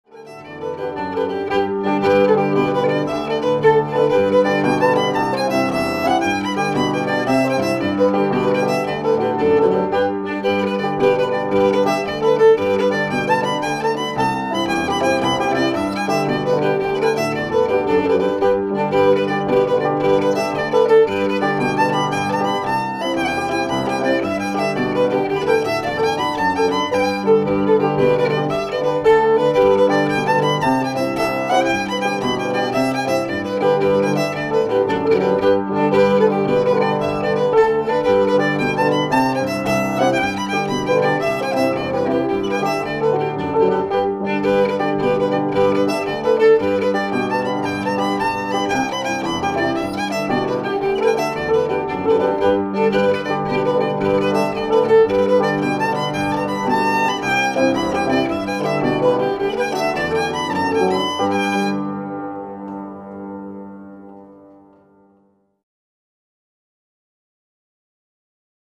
Jigs